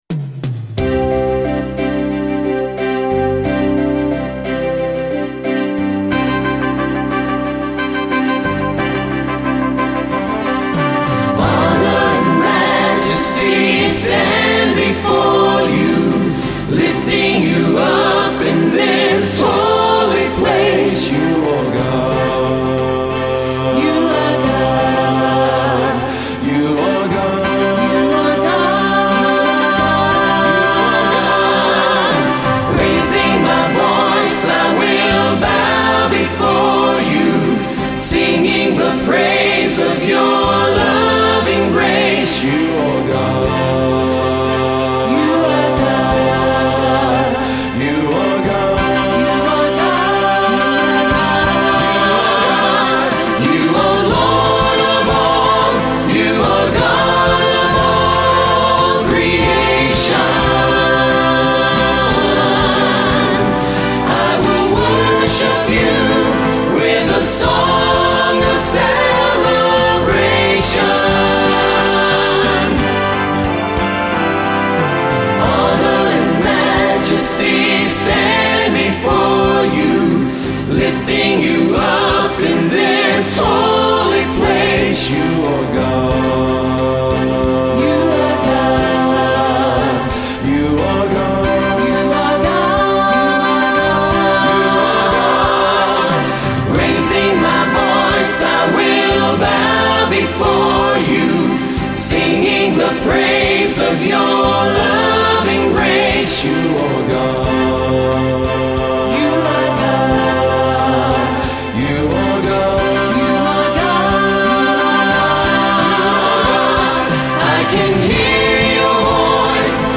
CONTEMPORARY STYLE
PRAISE ANTHEM
CHORAL ARRANGEMENT - STREAMING AUDIO for immediate listen.